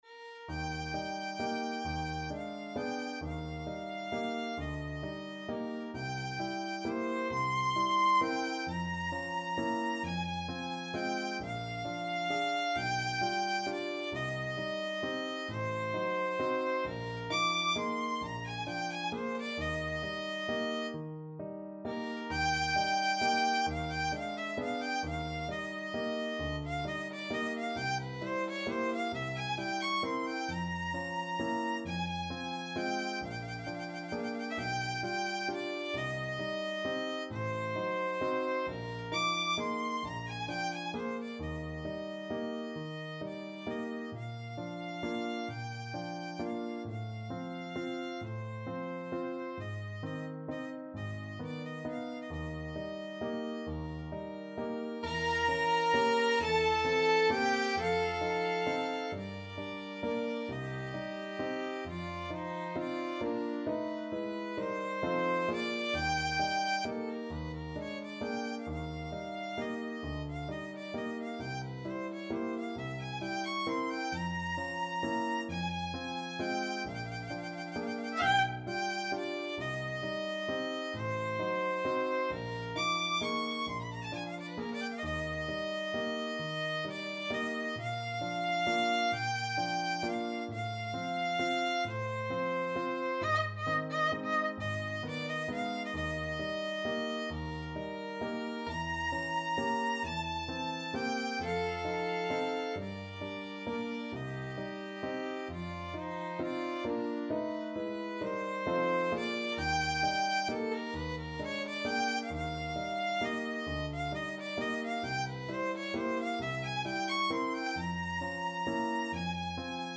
Free Sheet music for Violin
ViolinPiano
Eb major (Sounding Pitch) (View more Eb major Music for Violin )
12/8 (View more 12/8 Music)
Andante . = 44
Instrument:
Classical (View more Classical Violin Music)
Nostalgic Music for Violin